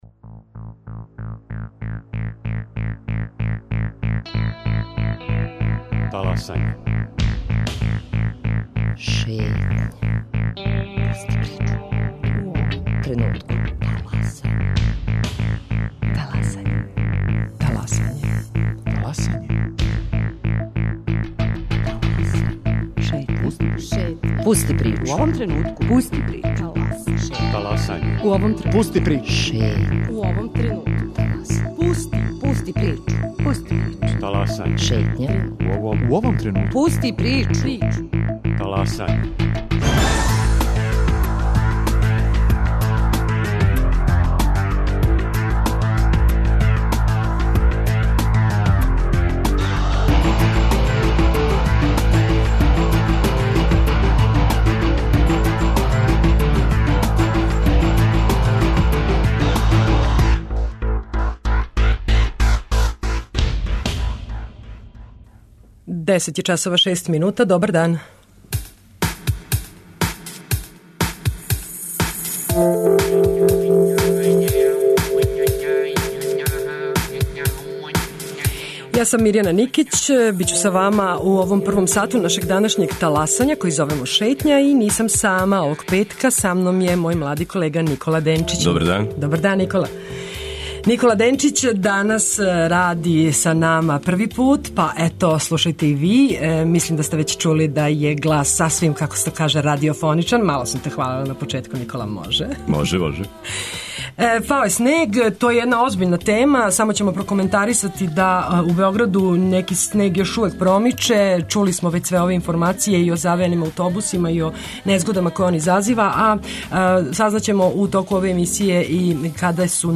музички новинар и рок критичар.